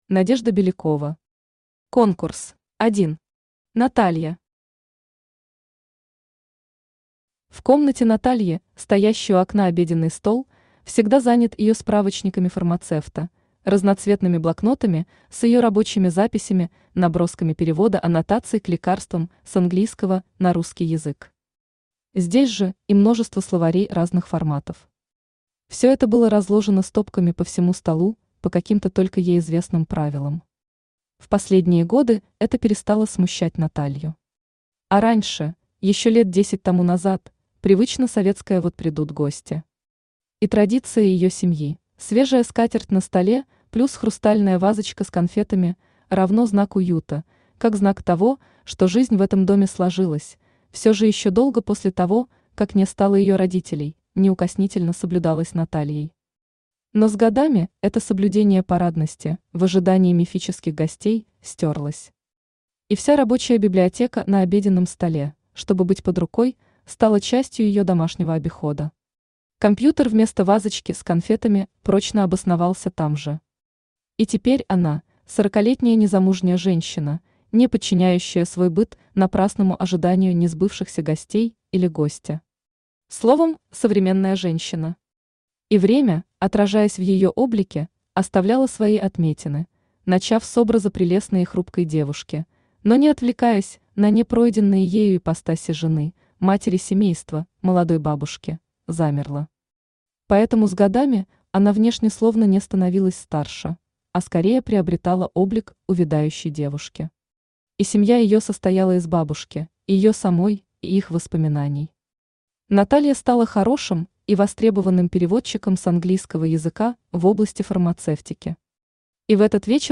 Aудиокнига Конкурс Автор Надежда Александровна Белякова Читает аудиокнигу Авточтец ЛитРес.